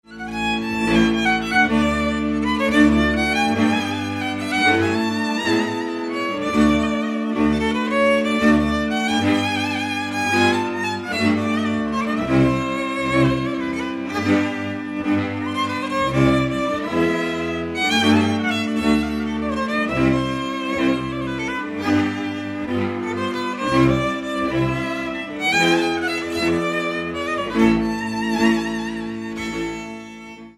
Dallampélda: Hangszeres felvétel
Erdély - Kis-Küküllő vm. - Vámosgálfalva
Műfaj: Asztali nóta
Stílus: 6. Duda-kanász mulattató stílus